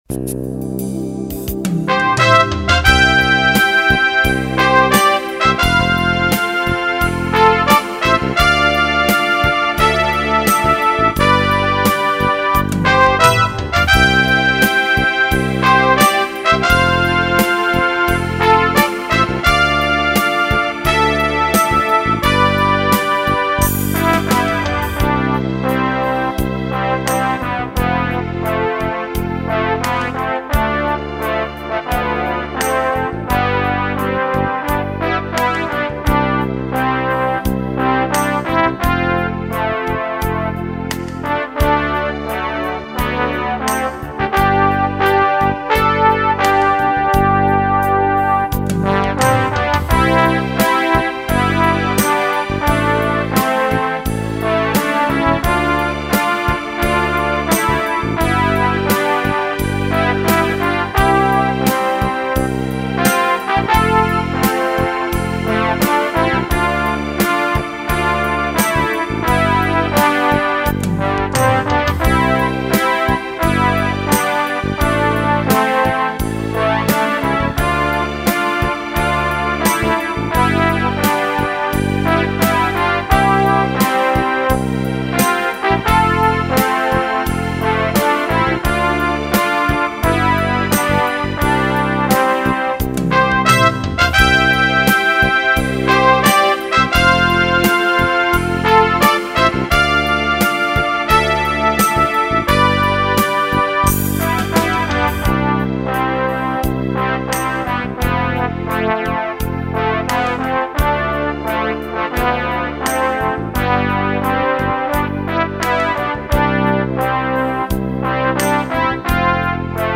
autor słów - p.Kazimierz Jan Dejer, muzyka - p. Zbigniew Łomański
HYMN SP STRZYŻÓW PODKŁAD
hymn_sp_strzyzow_podklad.mp3